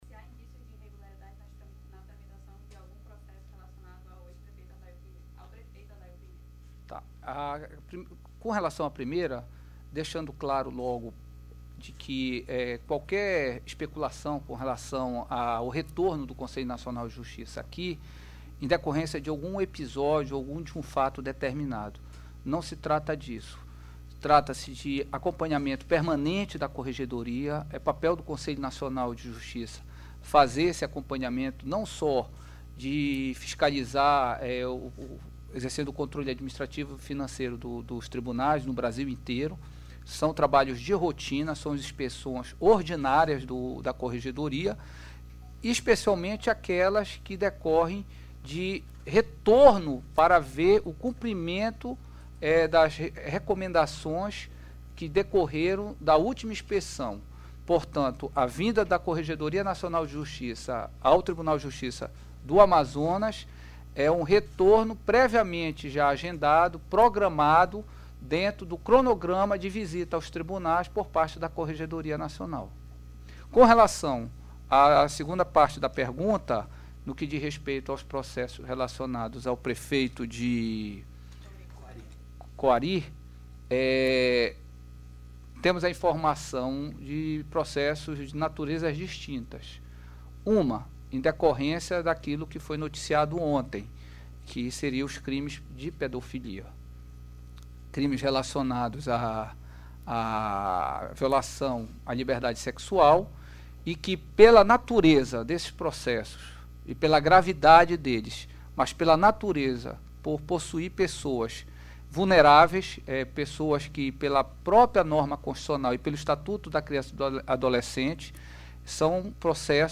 Entrevista__conselheiro_Gilberto.mp3